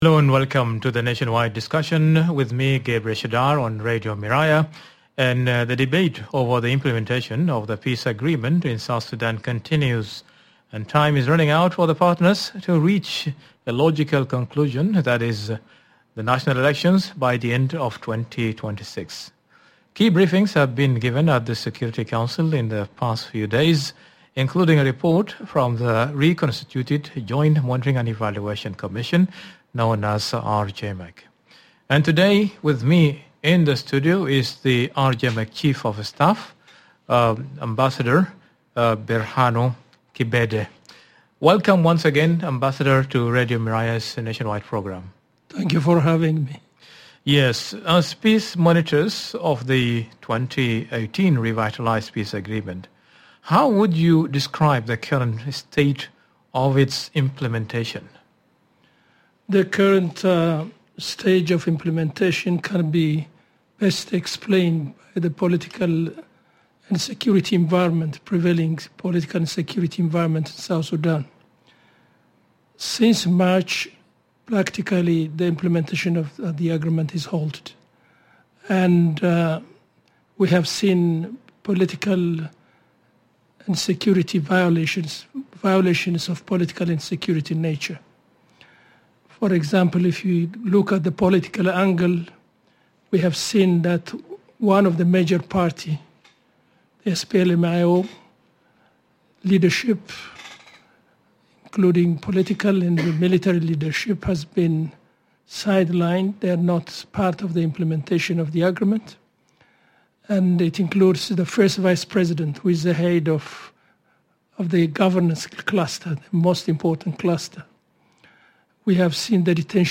South Sudan’s fragile peace hangs in the balance as delays and political deadlock threaten preparations for the 2026 elections. In this exclusive Radio Miraya interview, RJMEC Chief of Staff Ambassador Berhanu Kebede discusses the state of the peace agreement, the risks of renewed conflict, and the urgent need for inclusive political dialogue.